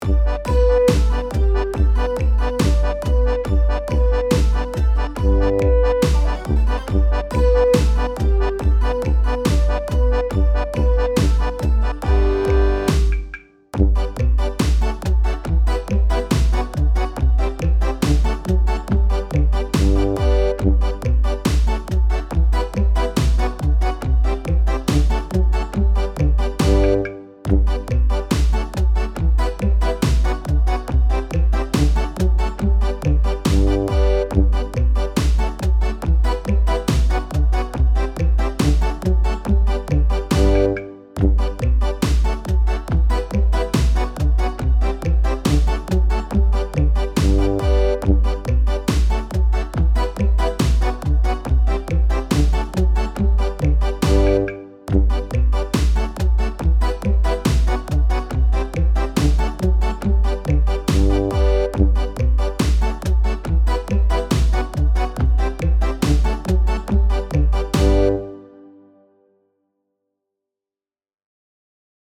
vrolijk – meezingversie | Nekka